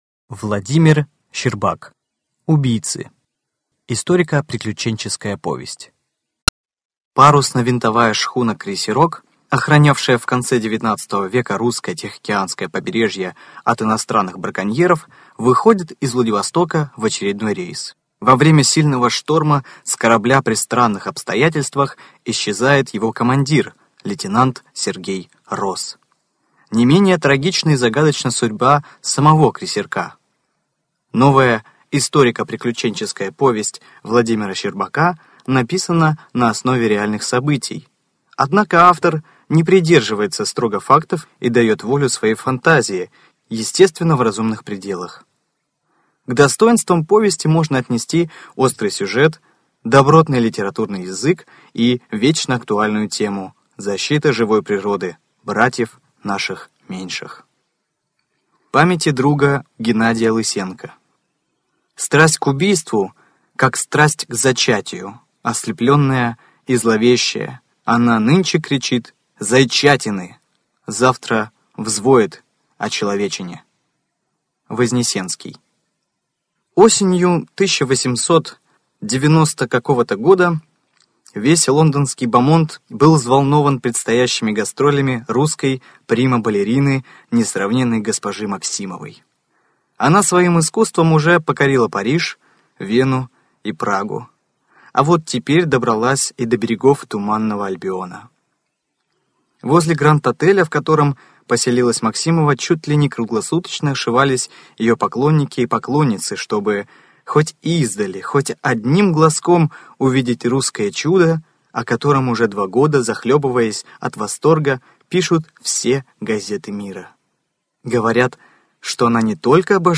Студия звукозаписиПриморская краевая библиотека для слепых